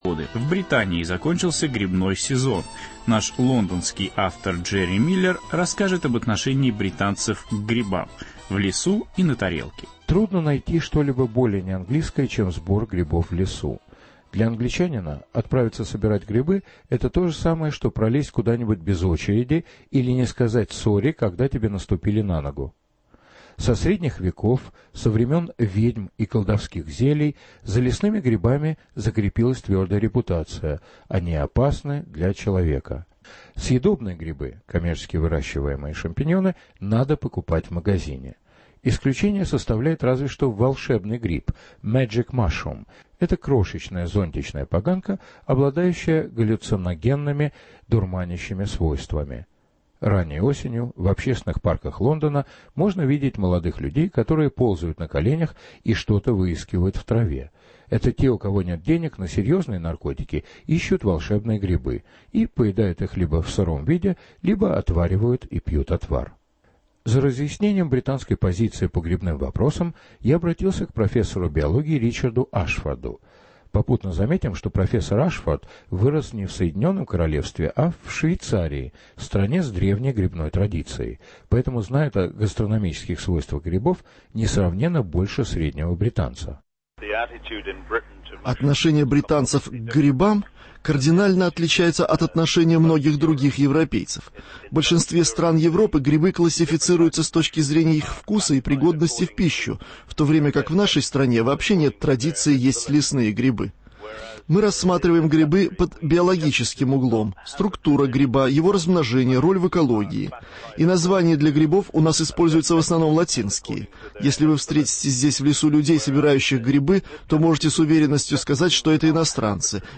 В Англию по грибы: беседа с экспертом